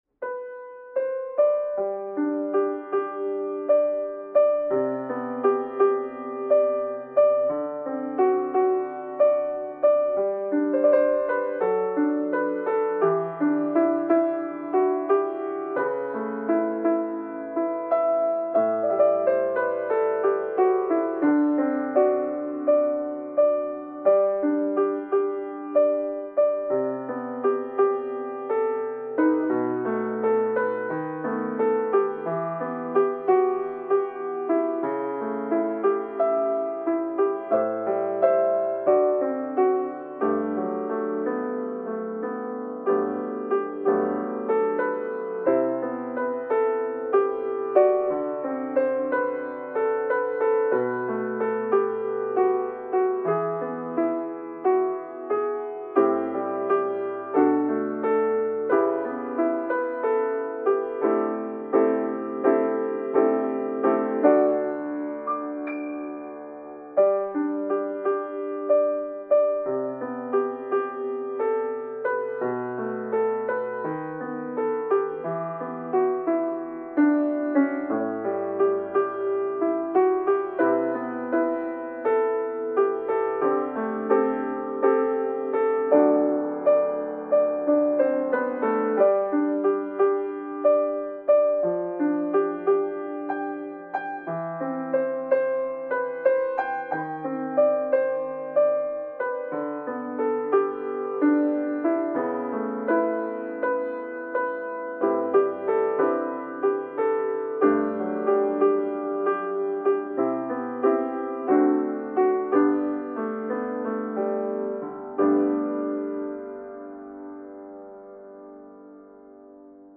YouTuber 向け、ピアノによる雑談配信向けBGMです。
生ピアノ（生演奏）
優しい